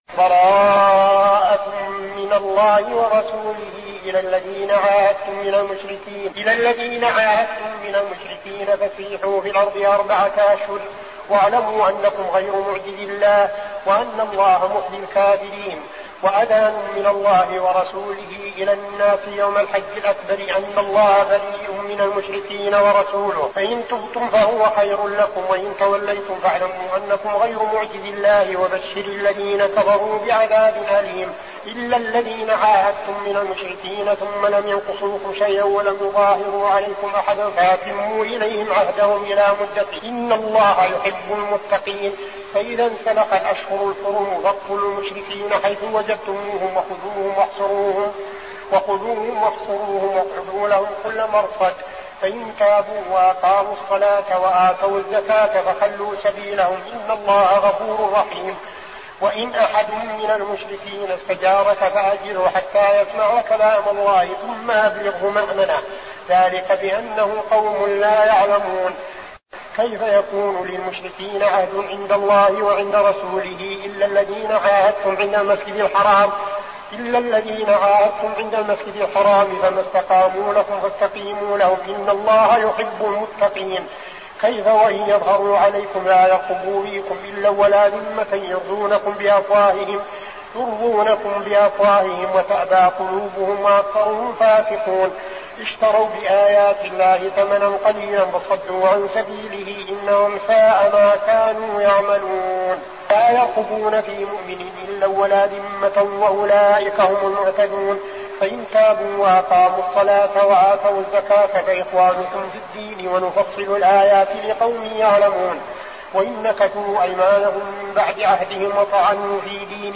المكان: المسجد النبوي الشيخ: فضيلة الشيخ عبدالعزيز بن صالح فضيلة الشيخ عبدالعزيز بن صالح التوبة